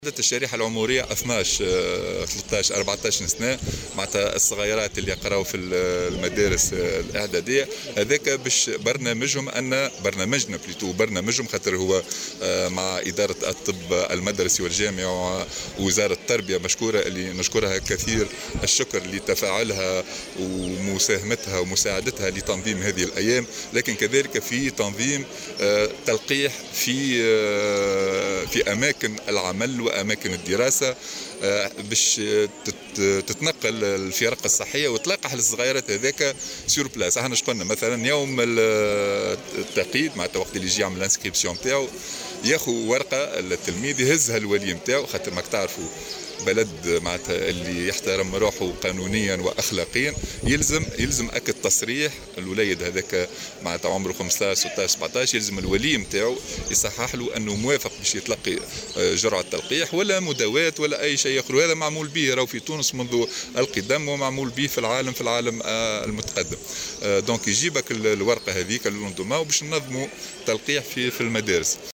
وأضاف في تصريح لمراسل "الجوهرة أف أم" على هامش زيارته اليوم لولاية القصرين، أنه يتعين على كل تلميذ عند التسجيل الاستظهار بوثيقة موافقة الولي ممضاة.